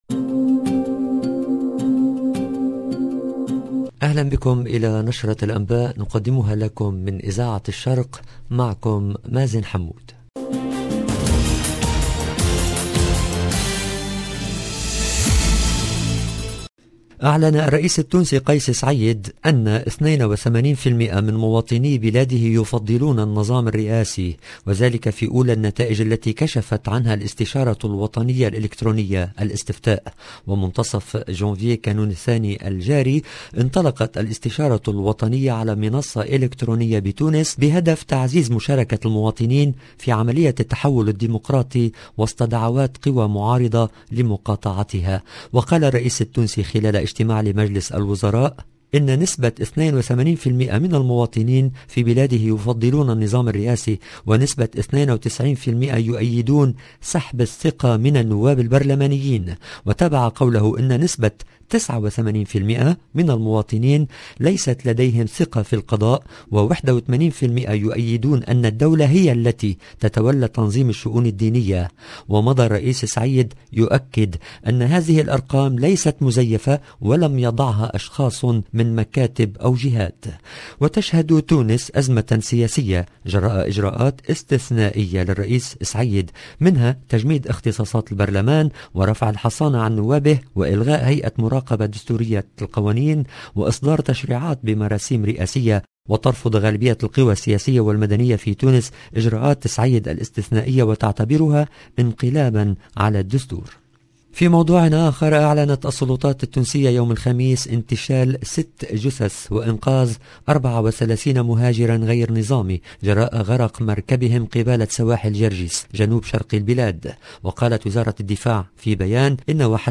LE JOURNAL DU SOIR EN LANGUE ARABE DU 28/01/22